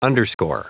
Prononciation du mot underscore en anglais (fichier audio)
Prononciation du mot : underscore